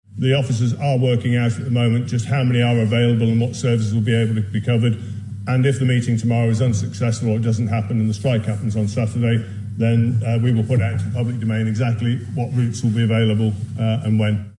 Mr Crookall adds that last-minute discussions are still taking place, but contingency plans will be implemented if necessary: